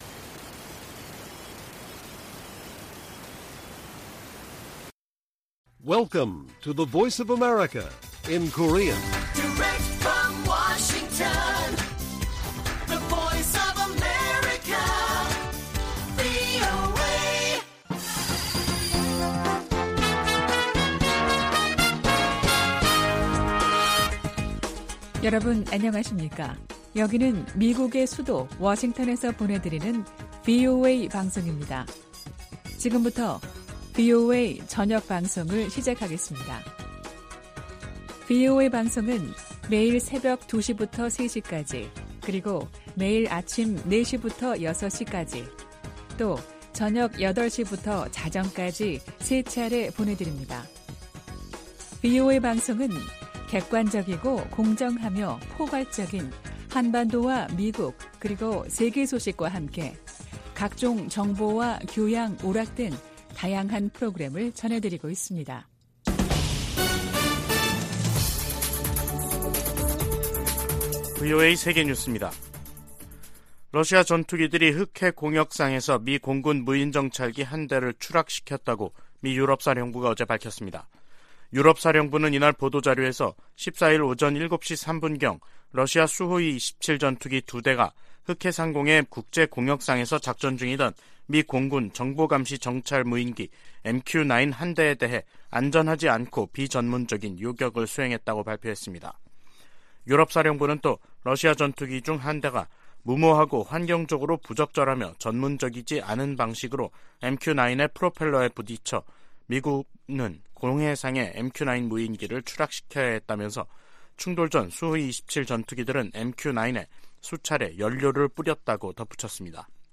VOA 한국어 간판 뉴스 프로그램 '뉴스 투데이', 2023년 3월 15일 1부 방송입니다. 북한은 14일 황해남도 장연에서 지대지 탄도미사일 2발 사격 훈련을 실시했다고 다음날 관영매체를 통해 발표했습니다. 북한의 최근 미사일 도발이 미한 연합훈련을 방해할 의도라면 성공하지 못할 것이라고 미 백악관이 지적했습니다.